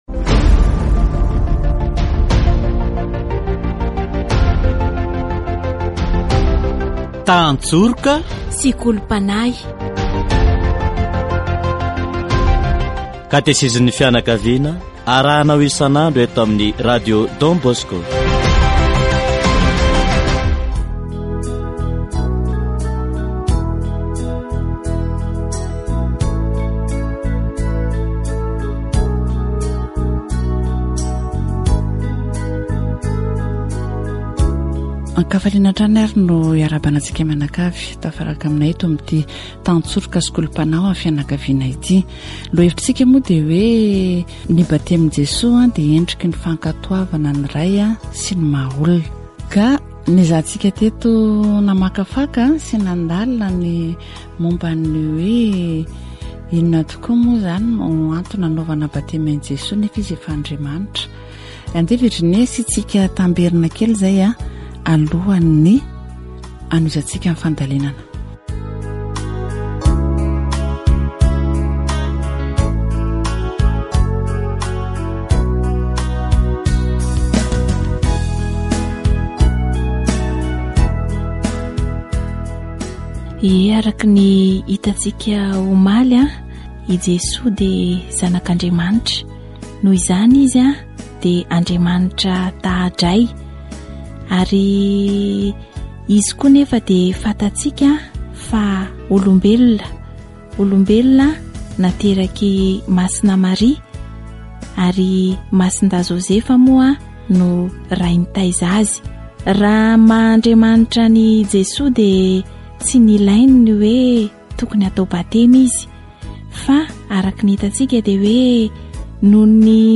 Mampahory ny zanaka ny safidy hitaiza irery ny zanaka, satria tsy ho feno ny fanabeazana io zaza io, sady fandavana tanteraka ny sakramentan'ny mariazy izany. Katesizy momba ny batemin'i Jesoa